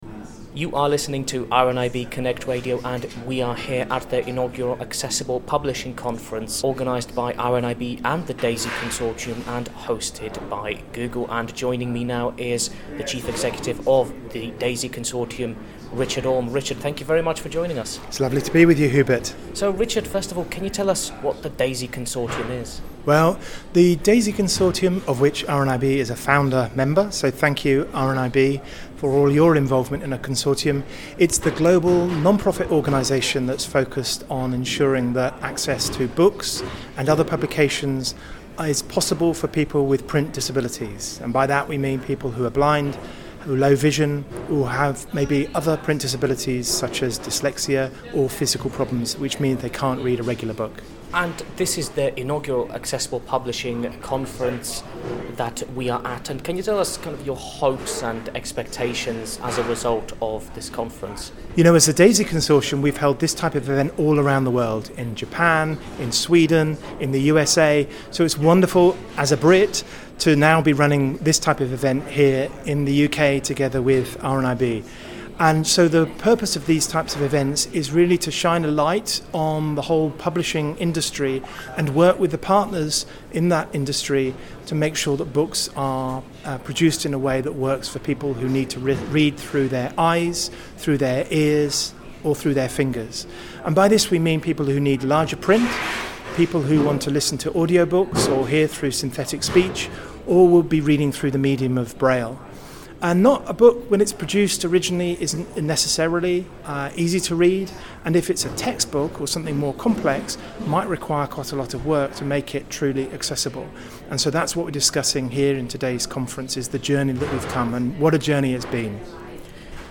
Accessible Publishing Conference: Conversation